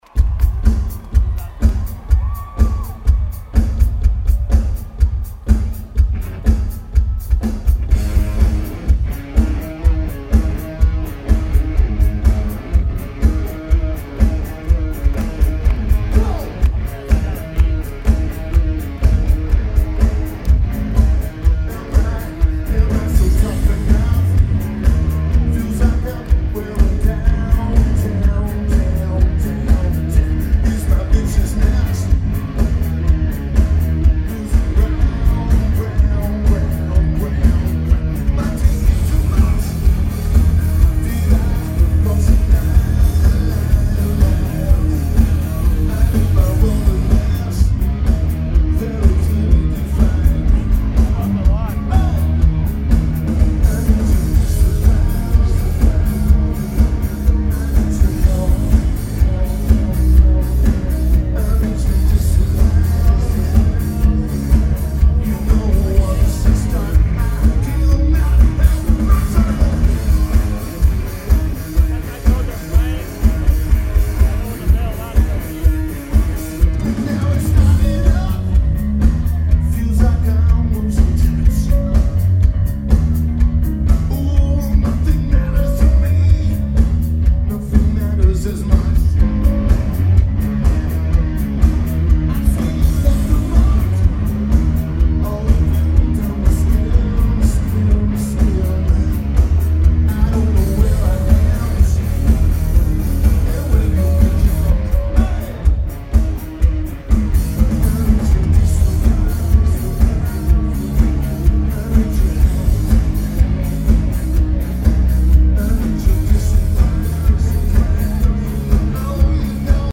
Shoreline Amphitheater
Lineage: Audio - AUD (Edirol R09 + Internals)